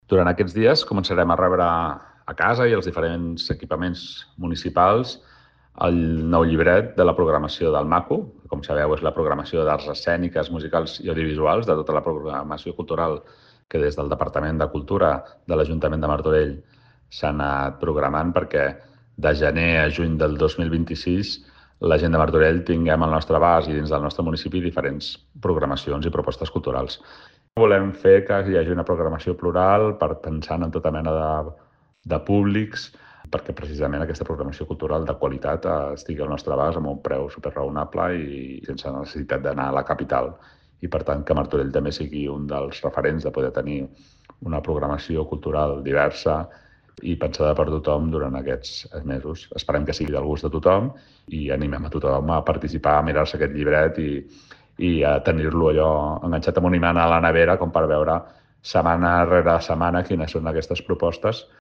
Sergi Corral, regidor de Cultura de l'Ajuntament de Martorell